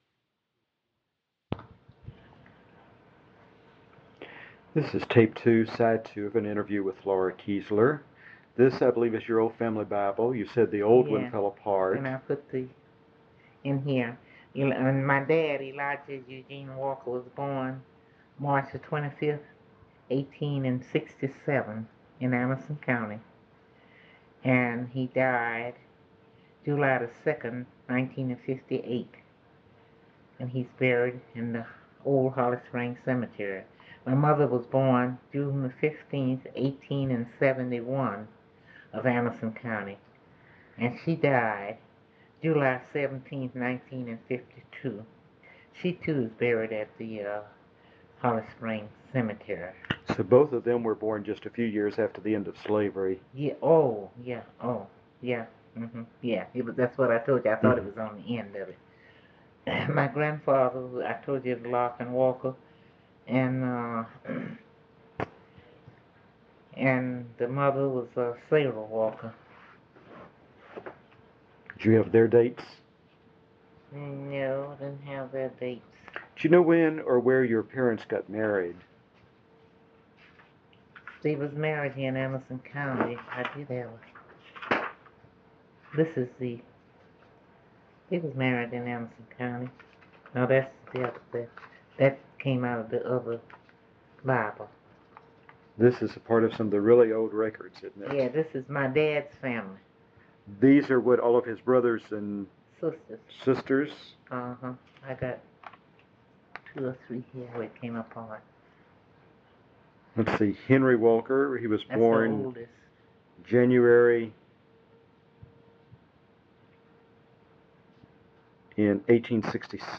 Cassette 2